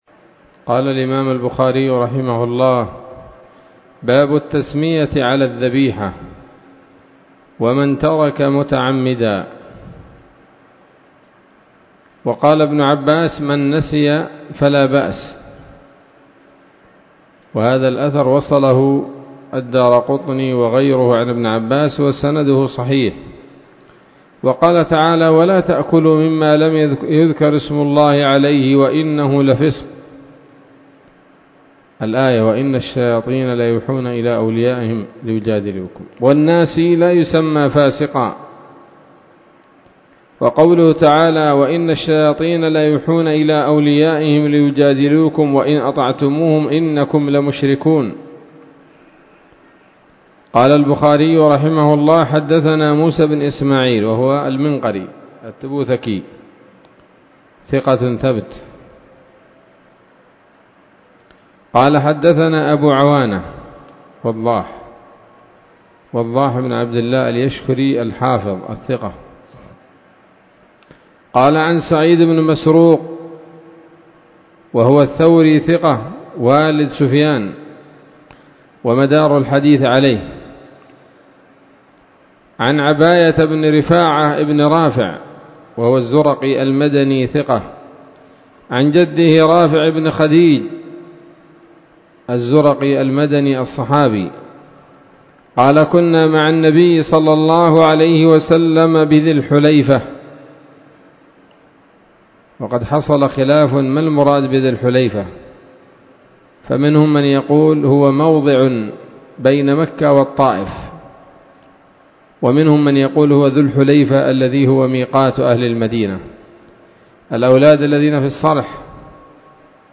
الدرس الثالث عشر من كتاب الذبائح والصيد من صحيح الإمام البخاري